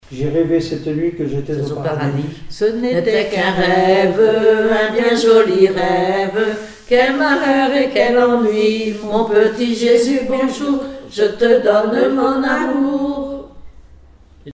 enfantine : prière, cantique
Chansons et formulettes enfantines
Pièce musicale inédite